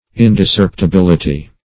Search Result for " indiscerptibility" : The Collaborative International Dictionary of English v.0.48: Indiscerpibility \In`dis*cerp`i*bil"i*ty\, Indiscerptibility \In`dis*cerp`ti*bil"i*ty\, n. The state or quality of being indiscerpible.
indiscerptibility.mp3